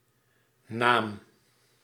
Ääntäminen
IPA: /naːm/